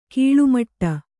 ♪ kīḷumaṭṭa